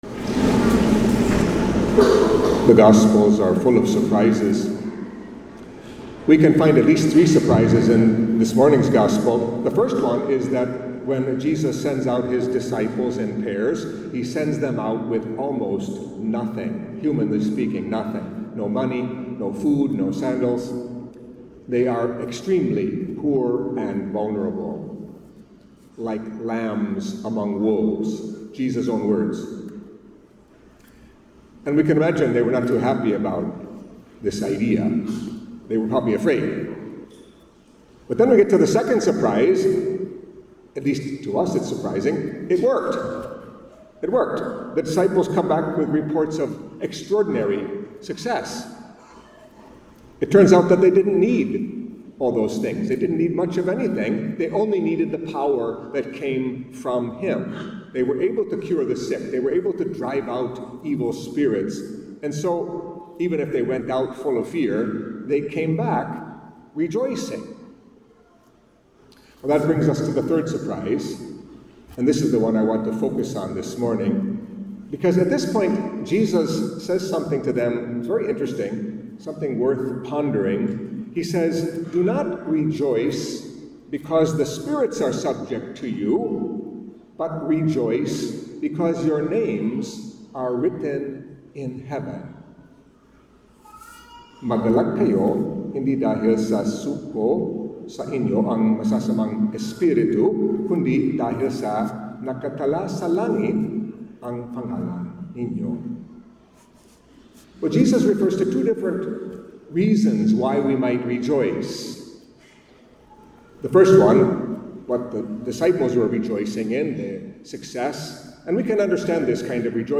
Catholic Mass homily for the Fourteenth Sunday in Ordinary Time